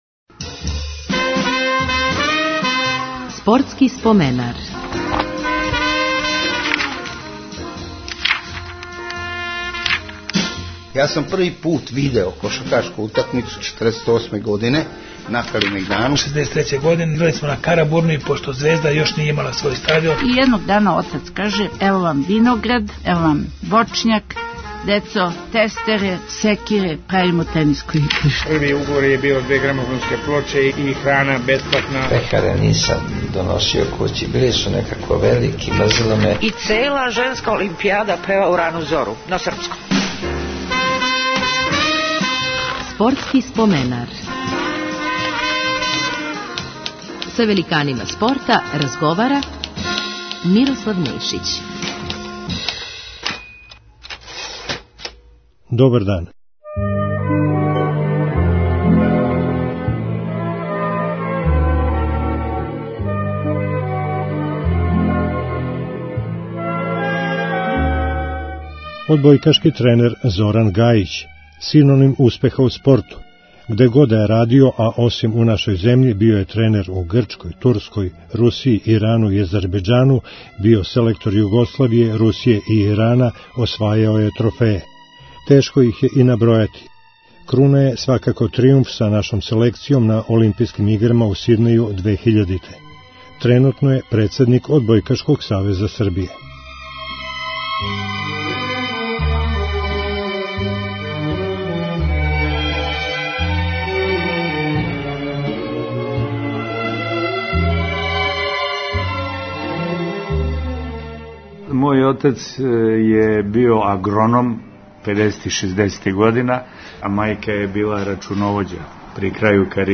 Гост 443. емисије је одбојкашки тренер Зоран Гајић.